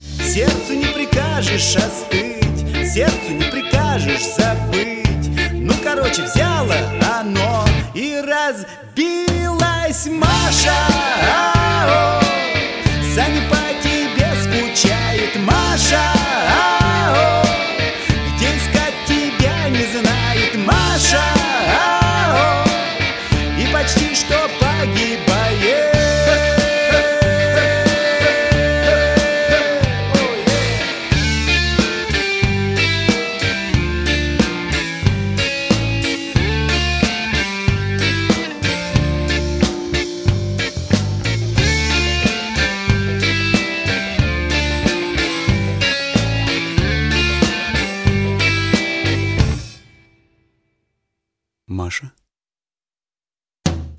• Качество: 128, Stereo
русский рок
пост-шансон